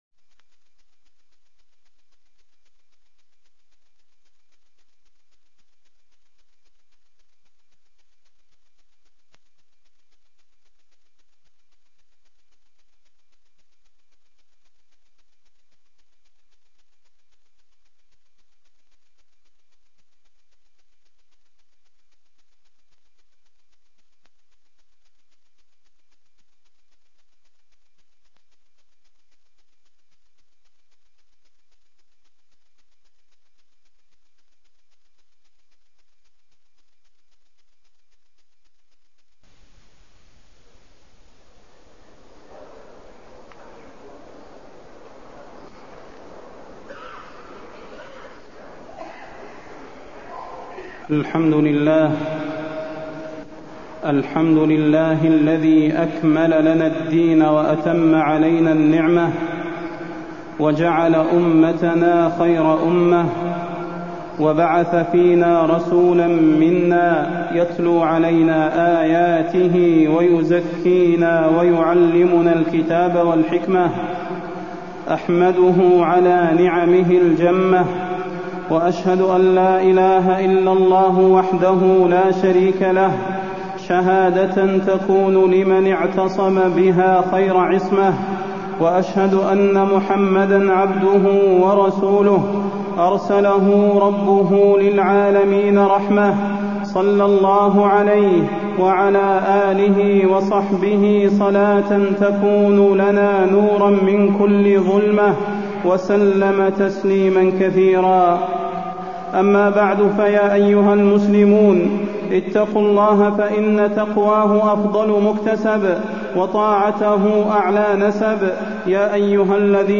تاريخ النشر ٢١ محرم ١٤٢٥ هـ المكان: المسجد النبوي الشيخ: فضيلة الشيخ د. صلاح بن محمد البدير فضيلة الشيخ د. صلاح بن محمد البدير الصلاة